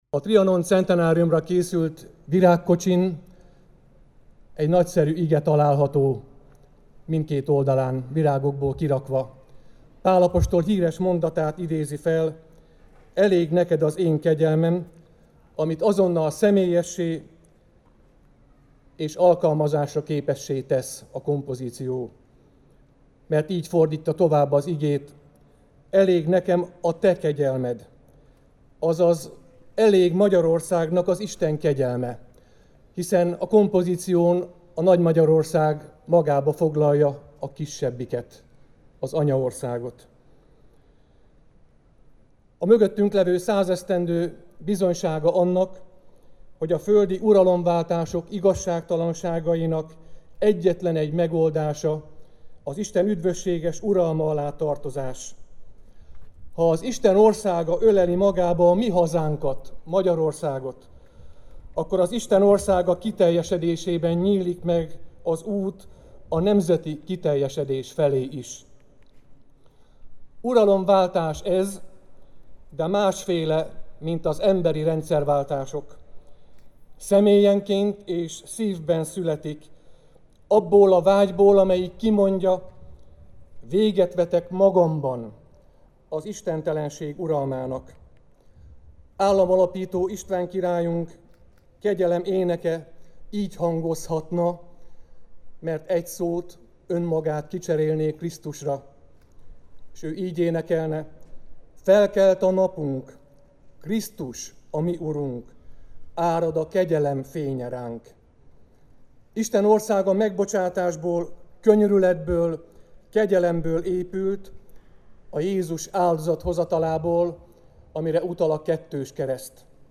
Kenyéráldás augusztus 20-án
Bár viszontagságos időket élünk és járványveszélyt viselünk, mégis áldást mondhatunk, hiszen az Isten gondviselő szeretete újra asztalt terít népünknek” – mondta Fekete Károly tiszántúli püspök augusztus 20-án Debrecen főterén a kenyéráldás alkalmával.
unnepi_kenyeraldas_puspok.mp3